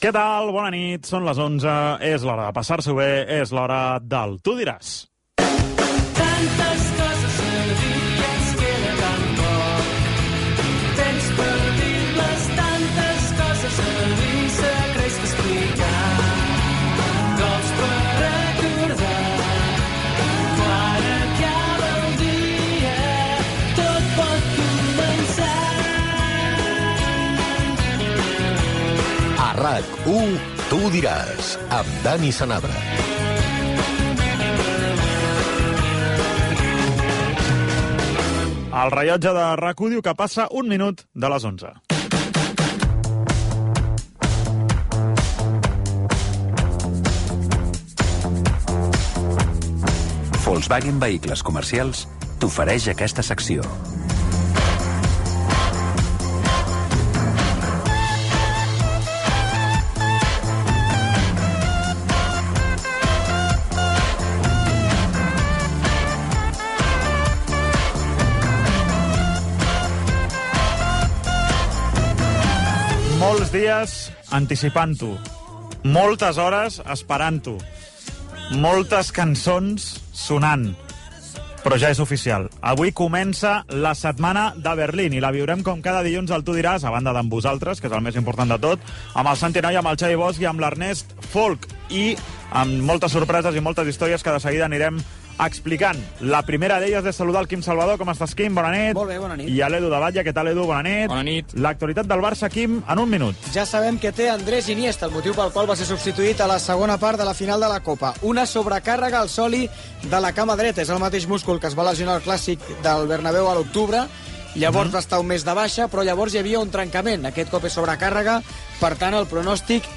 Hora, careta del programa, hora, actualitat del Futbol Club Barcelona i d'altres clubs i esports, publicitat, formes de participar en el programa.
Esportiu